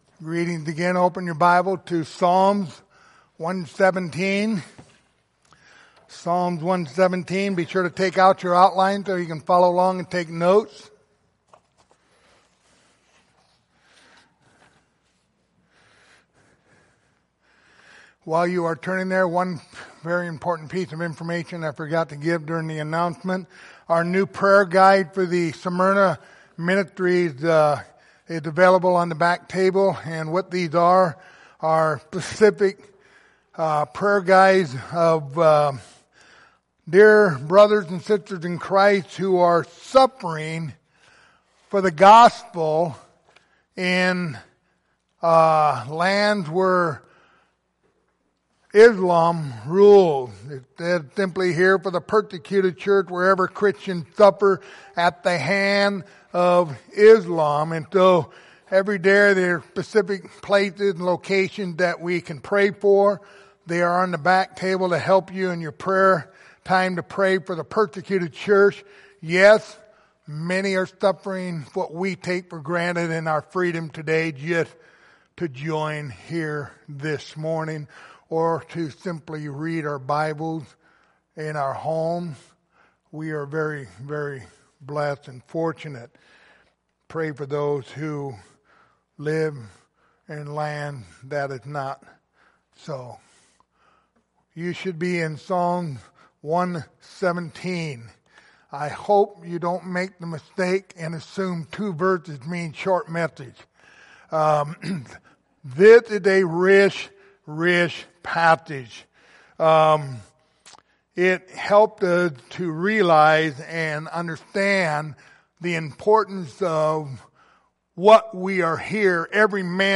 The book of Psalms Passage: Psalms 117:1-2 Service Type: Sunday Morning Topics